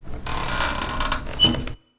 crowbar.wav